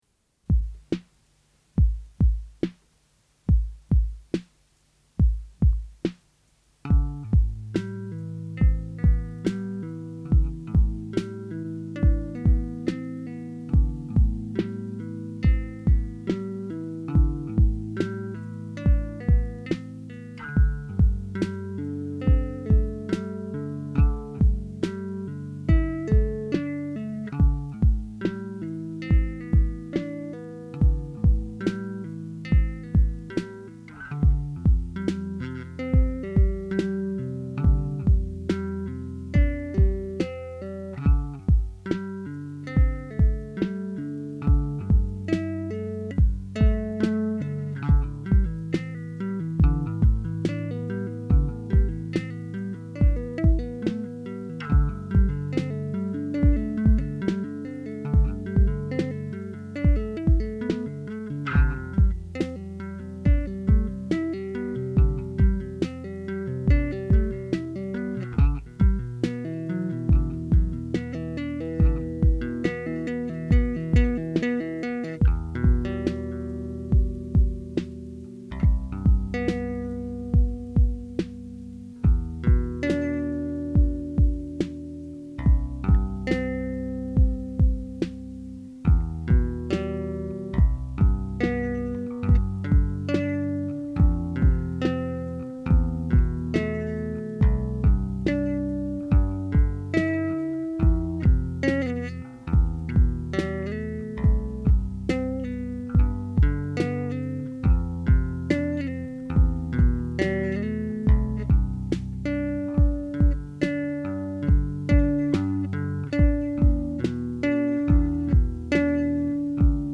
Funk, electronic, rock and weird Primus-y style bass stuff.
Up until now I’d been tracking in Midi and overdubbing live bits to cassette at mixdown.
early-2k-demos.mp3